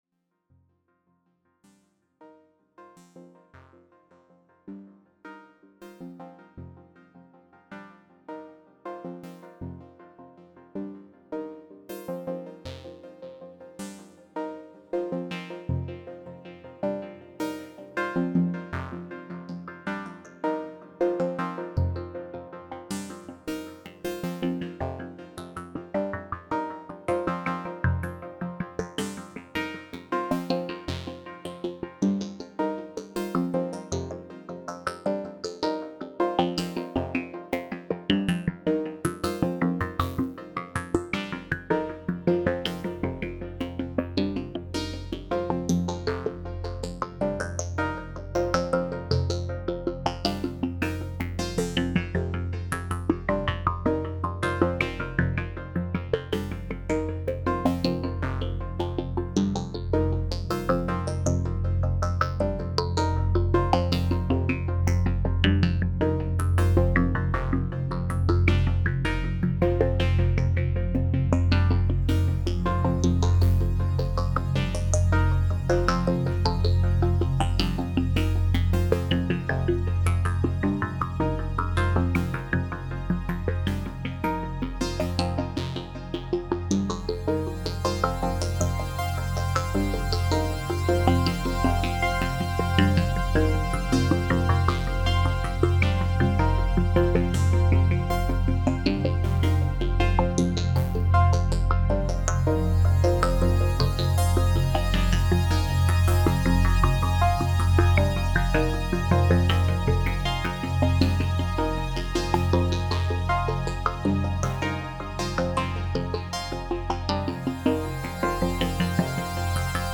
– vocal like sequence : modular syntheziser with Cwejman (RES-4) and Wiard/Malekko modules;
– slow evolving sequence : multi velocity samples patch from my fromer Oberheim Xpander;
– bass drone : Mutable Instruments Shruthi-1 w/ SMR-4 filter board;
– hi pitched pads : Mutable Instruments Ambika w/ SVF voicecards;
– effects : Ableton Live + Valhalladsp Shimmer reverb + Eventide Eclipse custom patch;
– assembled, produced and live mixing in Ableton Live.